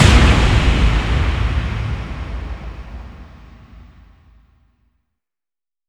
VEC3 FX Reverbkicks 23.wav